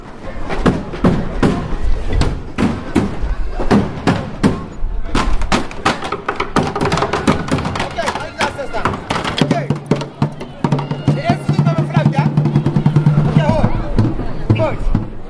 描述：A fieldrecording of celebrations of queensday in my hometown In Dutch: koninginnedag is a National feast with a lot of silly and funny very oldfashioned and sometimes idiotic but very pleasant habits and traditions.
标签： fieldrecording home koninginnedag queensday
声道立体声